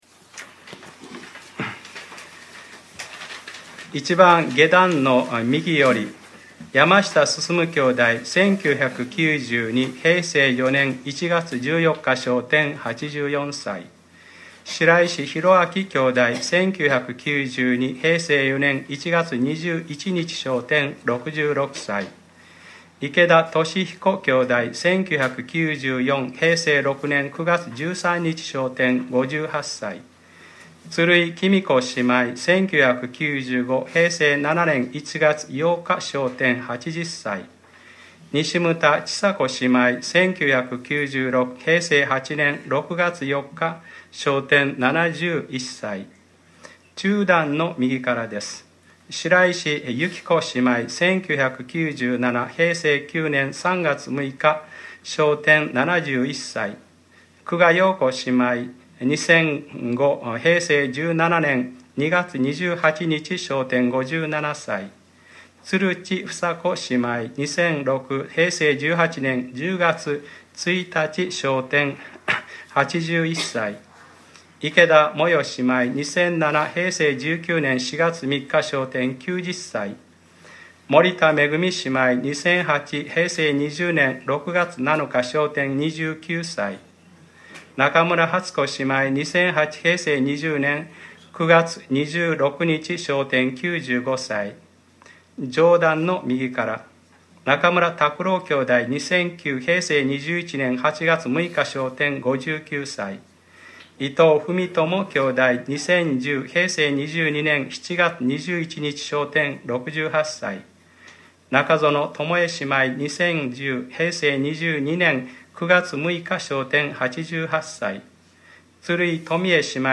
2015年04月05日）礼拝説教 『天国と永遠のいのち』